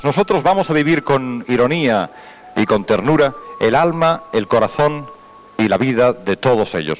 Transmissió, des de l'Hipódromo de la Zarzuela de Madrid, de la Fiesta de la Cadena SER amb motiu de l'estrena de la nova programació.